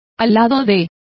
Complete with pronunciation of the translation of by.